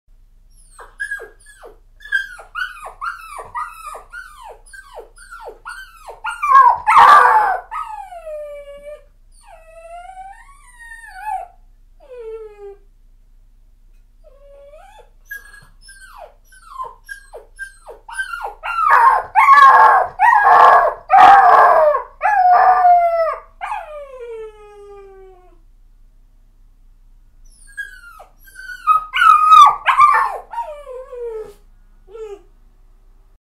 جلوه های صوتی
دانلود صدای گریه کردن سگ 1 از ساعد نیوز با لینک مستقیم و کیفیت بالا